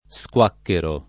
vai all'elenco alfabetico delle voci ingrandisci il carattere 100% rimpicciolisci il carattere stampa invia tramite posta elettronica codividi su Facebook squacquerare v.; squacquero [ S k U# kk U ero ] — antiq. squaccherare : squacchero [ S k U# kkero ]